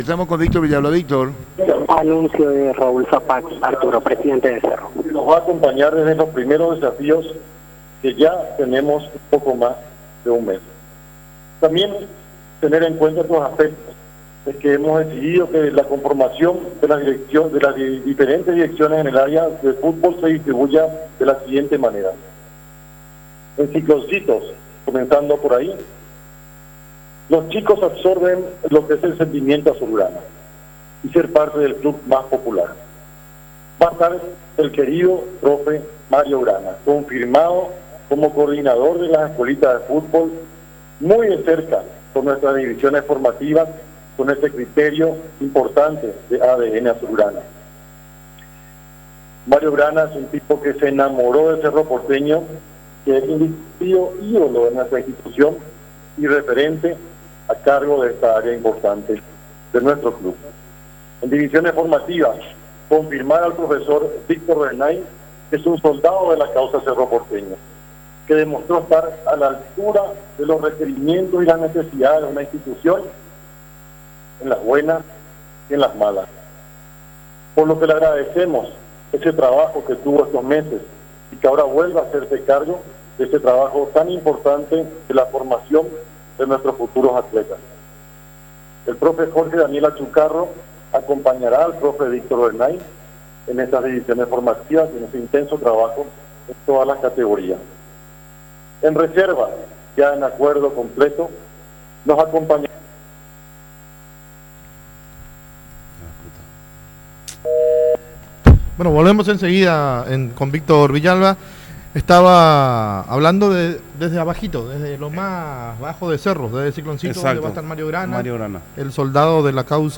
anunció en conferencia de prensa